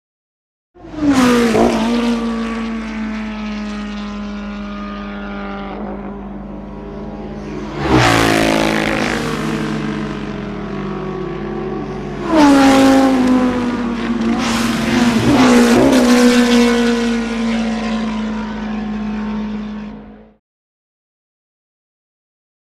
Motorcycles; By; 1000 Cc And 500 Cc Motorbikes Fast Past.